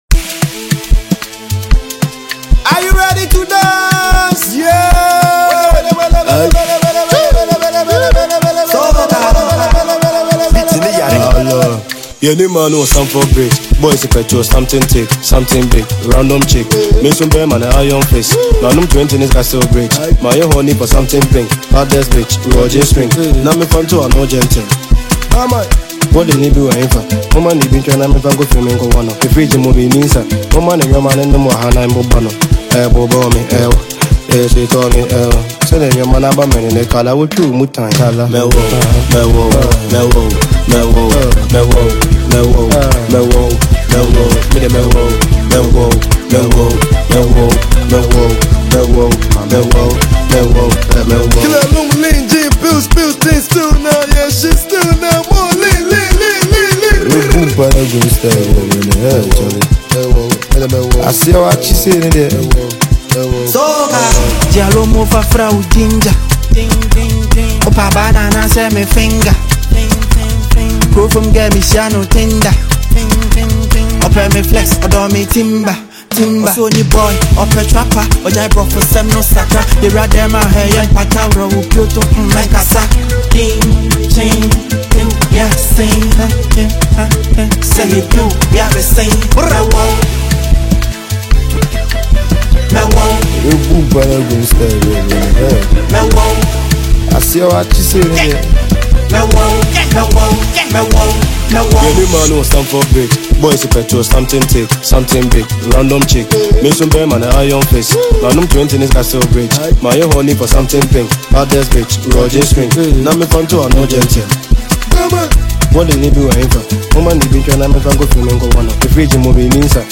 Ghanaian drill
aggressive flow cutting through the heavy drill production